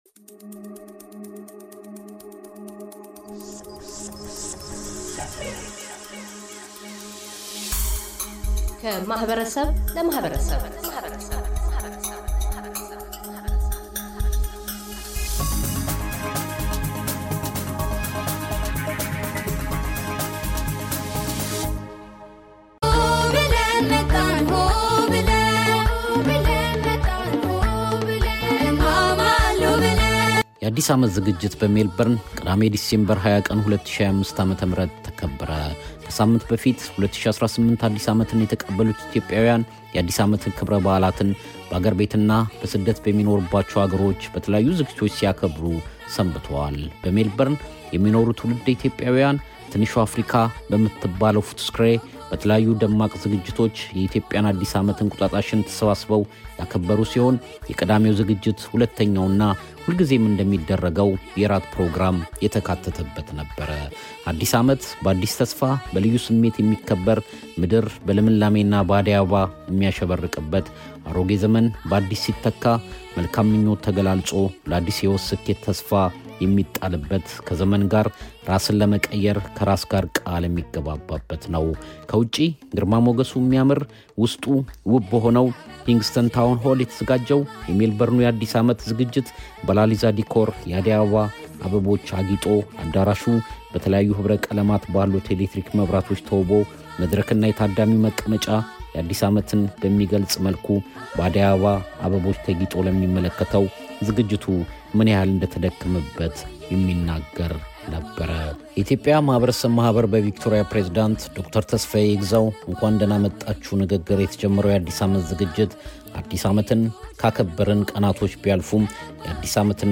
የኢትዮጵያውያን 2018 አዲስ ዓመት አከባበር በኬንሲንግተን ማዘጋጃ ቤት አዳራሽ ሜልበርን አውስትራሊያ።